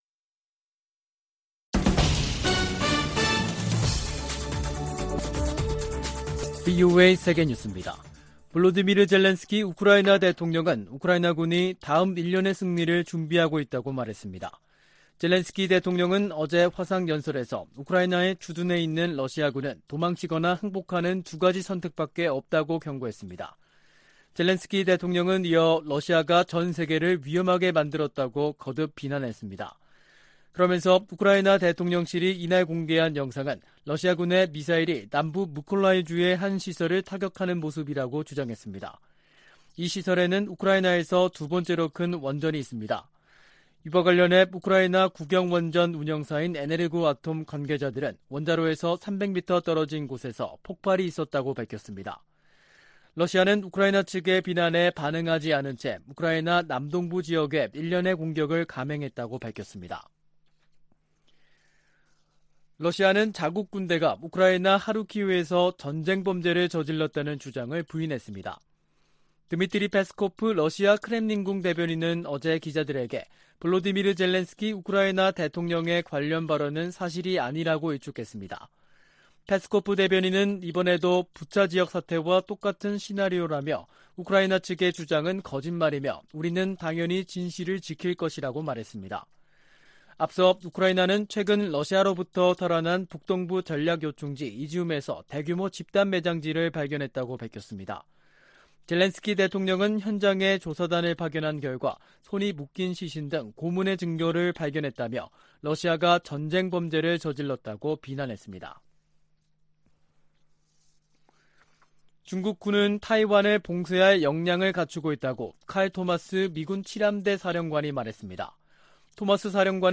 VOA 한국어 간판 뉴스 프로그램 '뉴스 투데이', 2022년 9월 20일 3부 방송입니다. 한국이 북한에 제안한 ‘담대한 구상’은 대화 초기부터 북한이 우려하는 체제안보와 정치, 군사적 문제를 논의할 수 있다는 취지라고 권영세 한국 통일부 장관이 말했습니다. 미 국방부가 향후 5년간 중국과 북한 등의 대량살상무기 위협 대응으로 억지, 예방, 압도적 우위의 중요성을 강조했습니다. 최근 중국 항구에 기항하는 북한 선박이 늘고 있는 것으로 나타났습니다.